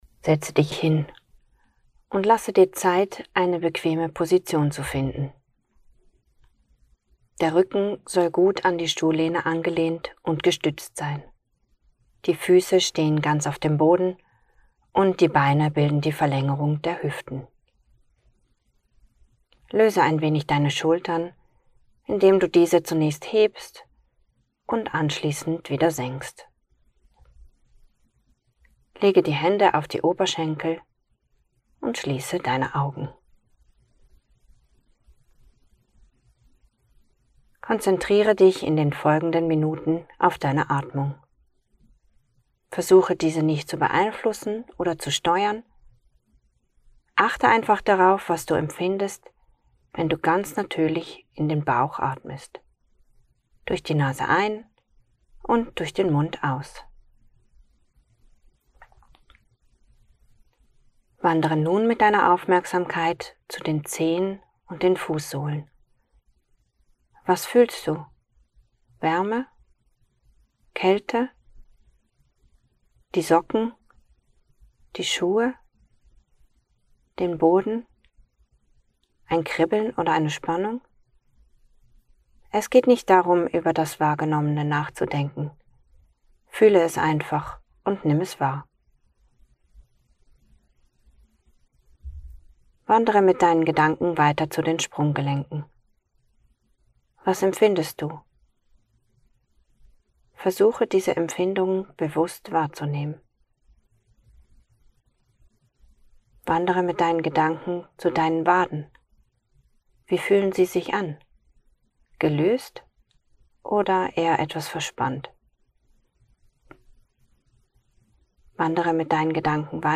Bodyscan ohne Musik